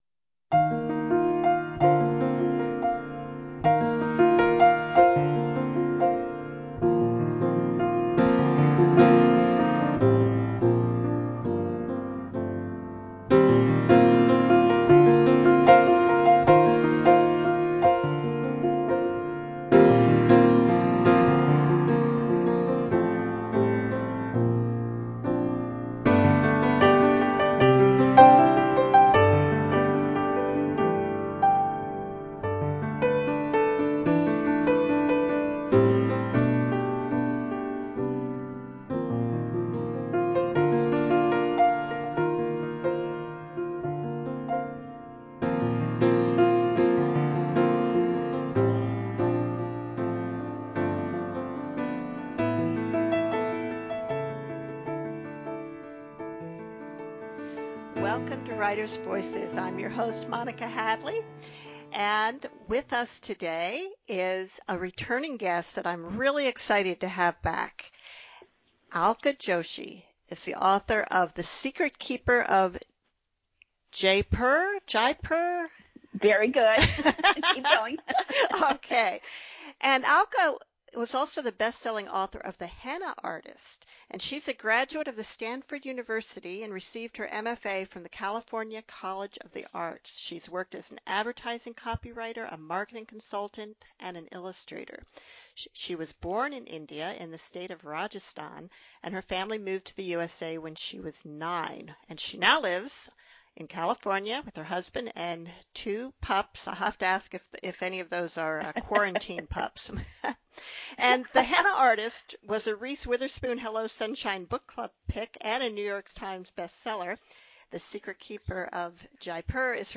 She talked with us about her novels and her writing process.